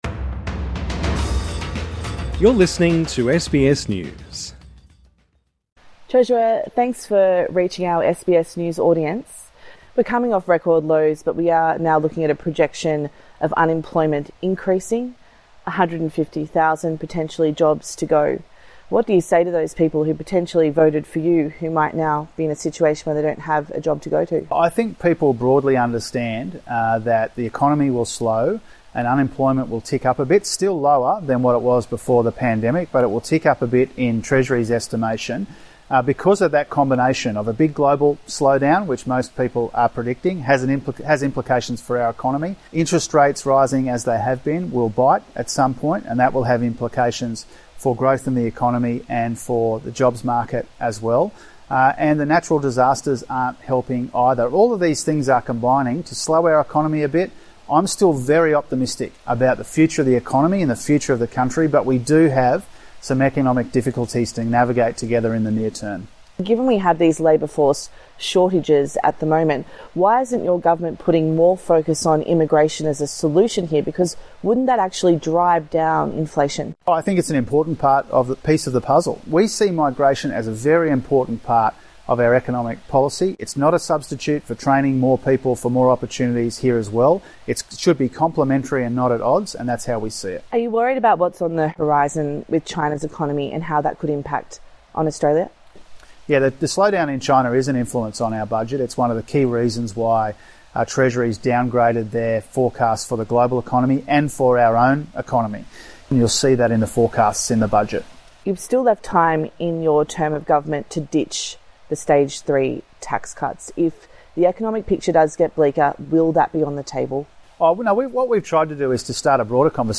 Federal Treasurer Dr Jim Chalmers Source: SBS News